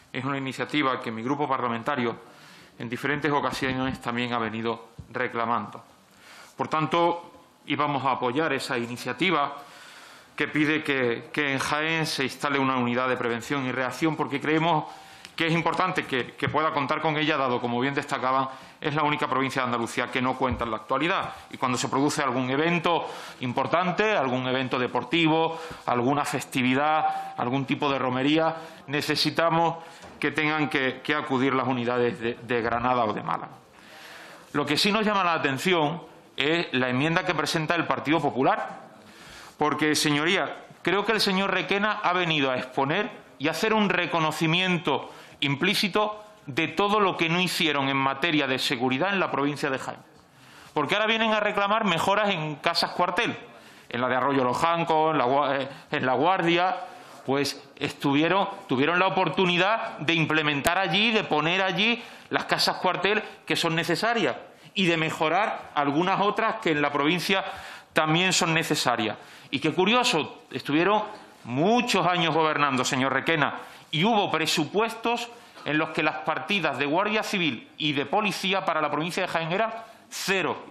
En Comisión de interior
Cortes de sonido